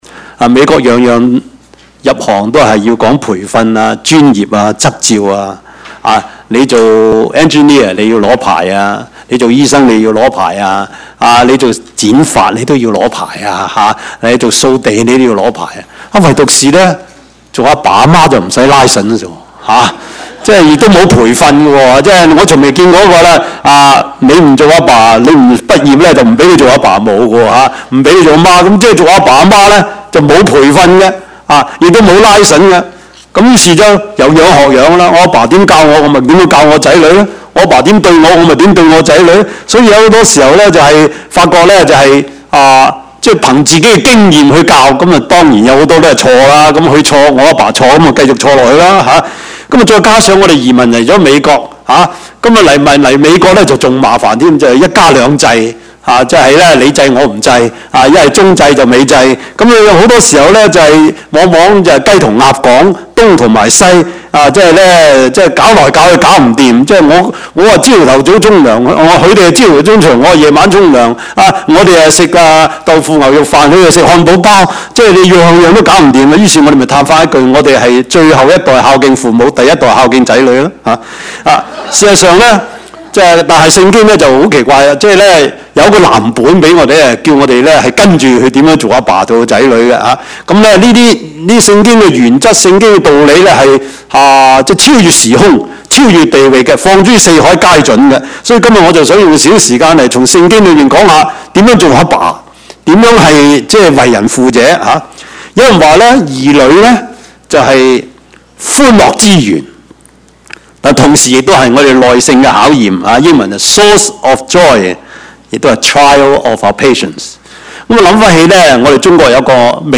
Service Type: 主日崇拜
Topics: 主日證道 « 牛皮燈籠 表裏一致的信仰 »